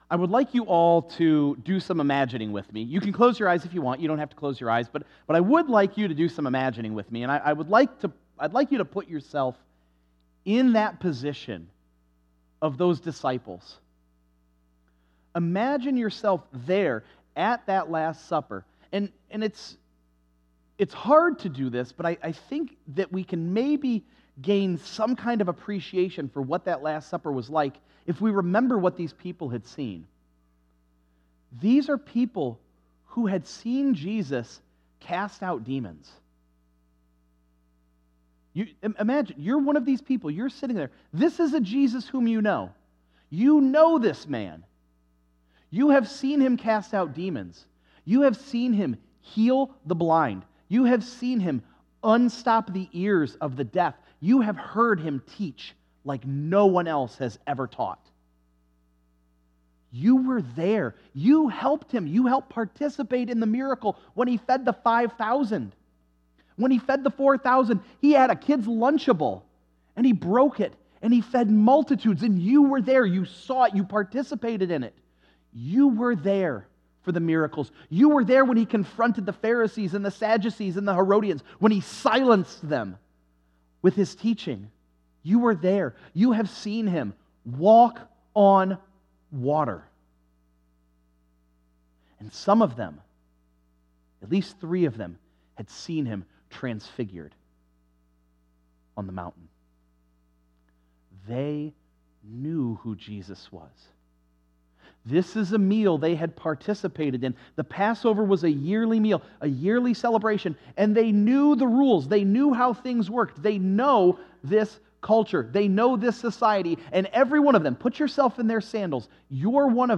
9_29_24_sunday_sermon.mp3